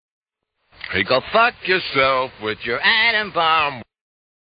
rock psichedelico
Velocizzato e rovesciato